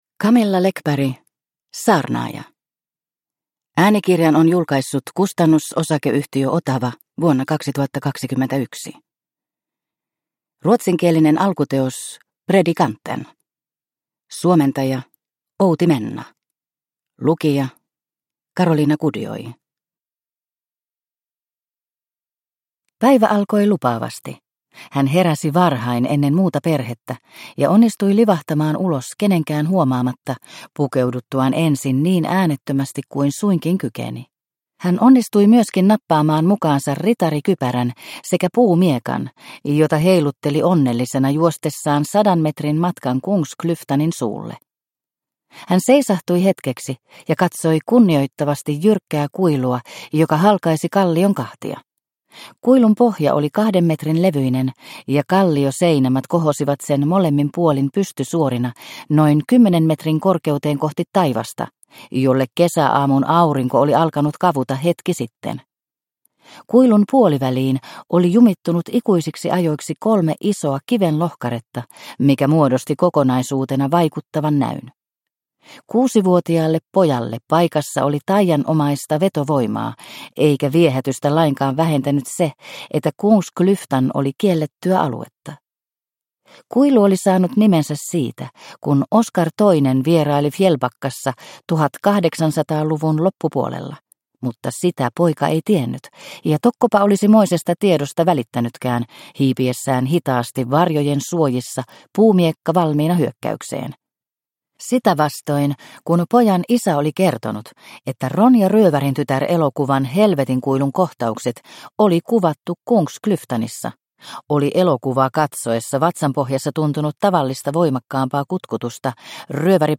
Saarnaaja – Ljudbok – Laddas ner